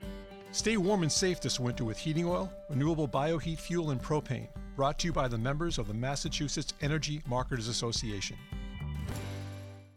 • January 2026 Heating Oil Industry Radio: 10 Second Spot